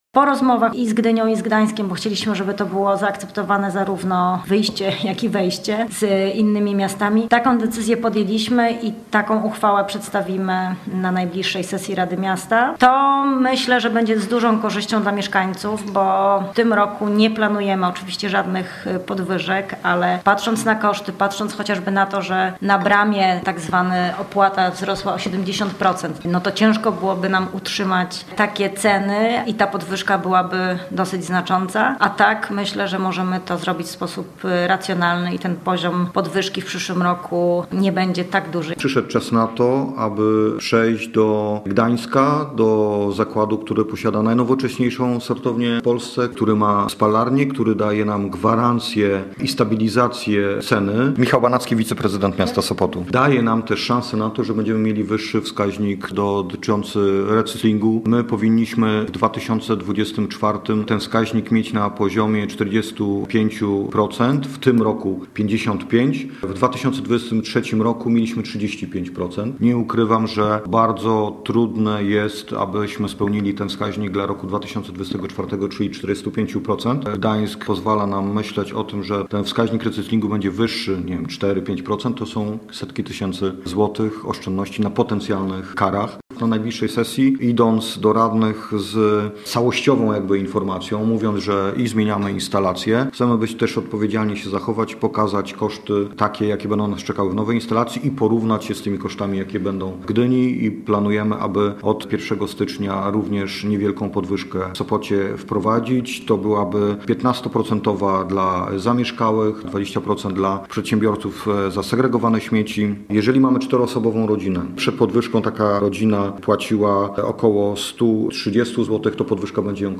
Posłuchaj materiału reportera: